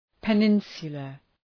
Προφορά
{pə’nınsələ}